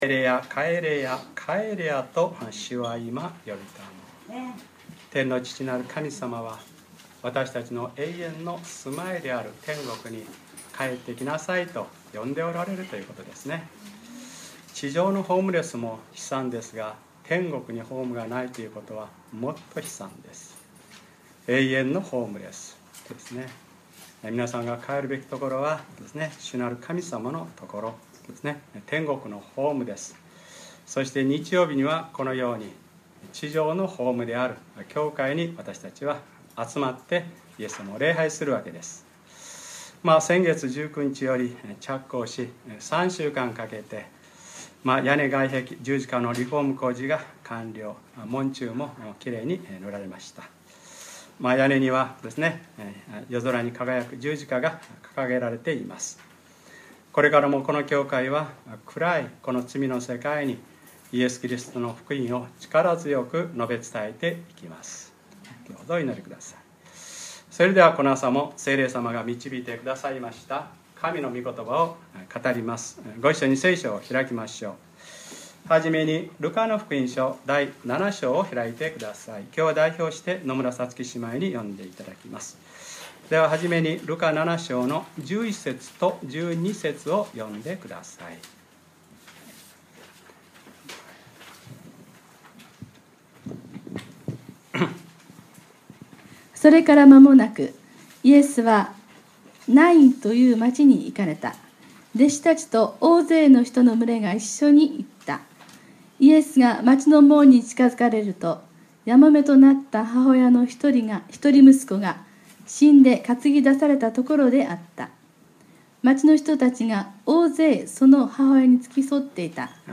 2013年8月11日(日）礼拝説教 『ルカｰ２３ “泣かなくてもよい”』